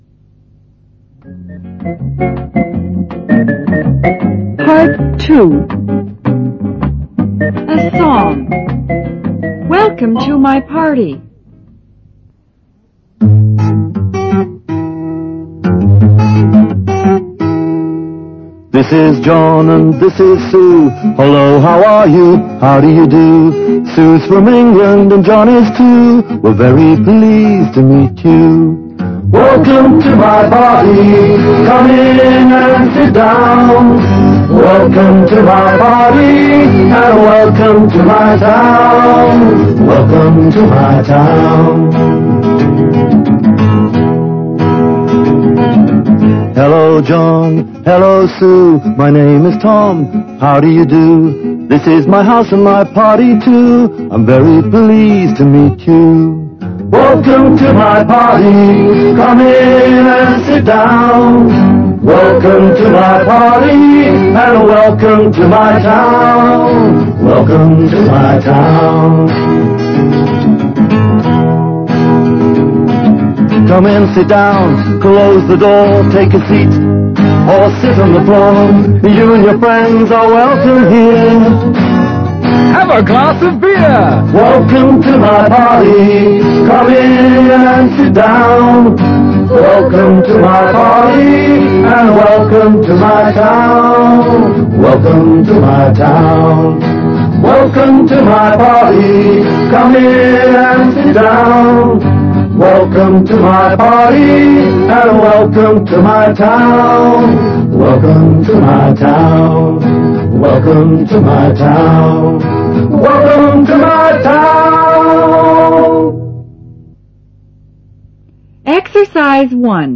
PART II A Song-Welcome To My Party